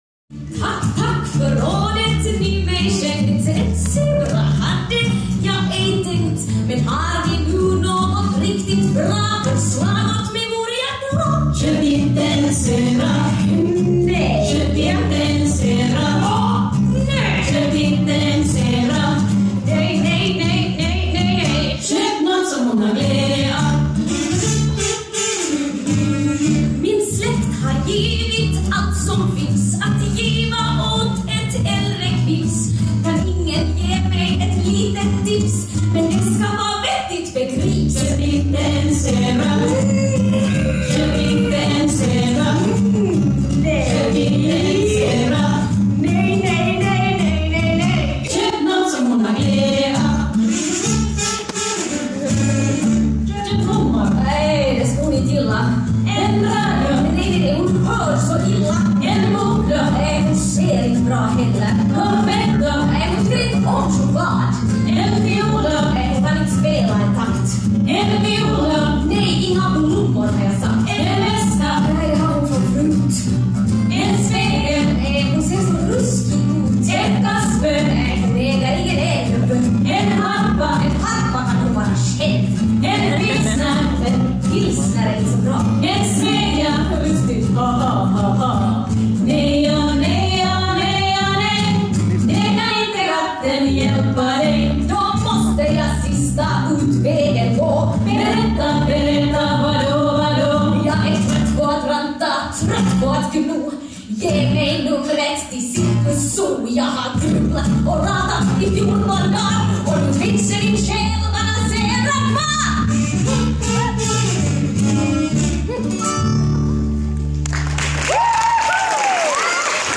From the concert Four Voices in 2016 Köp inte en Zebra (Povel Ramel) 4voices Live 2016 (början fattas)